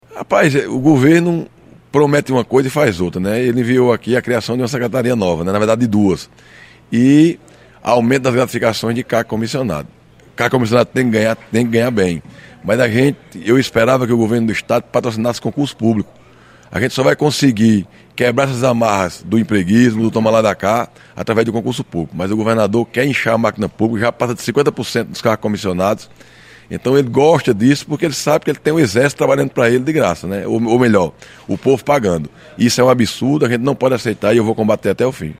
O líder da bancada de oposição na Casa, o deputado estadual Wallber Virgolino (PL), criticou a criação das pastas e acusou a ALPB de priorizar apenas as pautas do Governo. Os comentários foram registrados pelo programa Correio Debate, da Rádio Correio 98 FM, desta terça-feira.